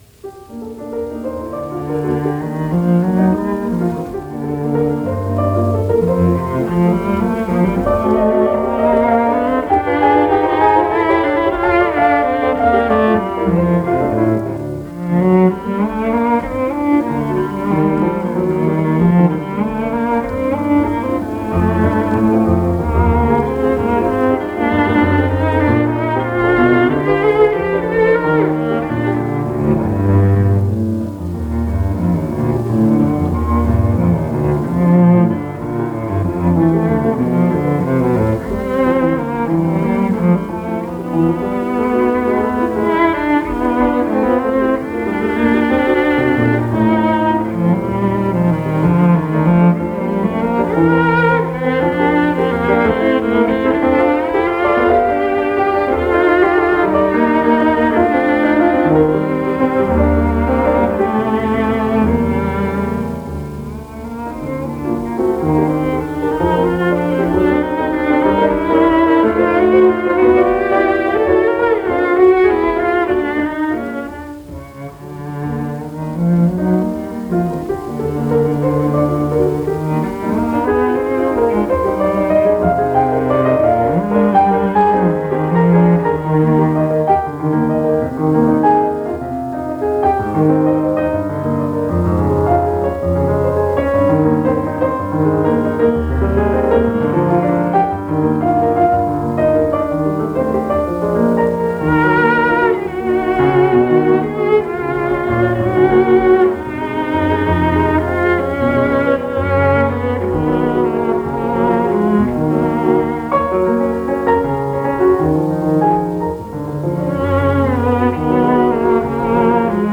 André Navarra and Annie d'Arco play music of Gabriel Fauré - ORTF session - Paris - 1951 - Past Daily Weekend Gramophone -
Sonata Number 2 for Cello and Piano with 2 legends: André Navarra, cello and Annie D’Arco, piano – in session for ORTF in Paris in 1951.
Fauré-Sonata-Number-for-Cello-and-Piano.mp3